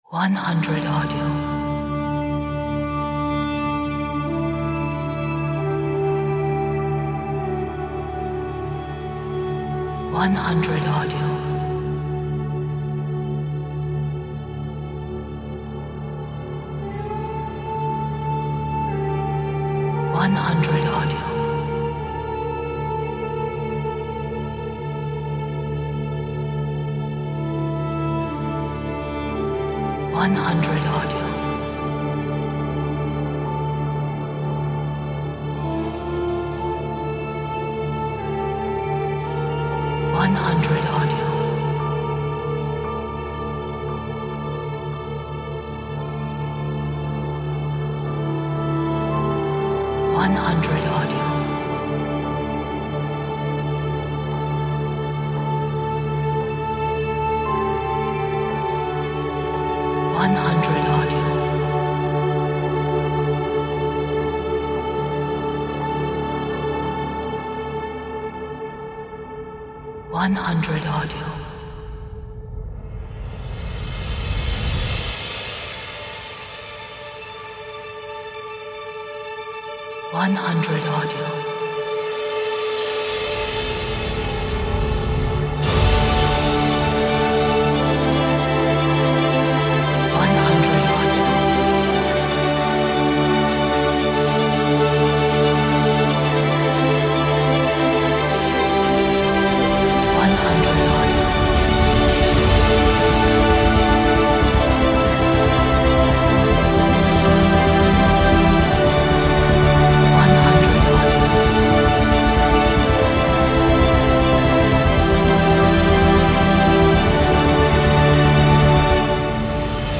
Beautiful and inspirational trailer!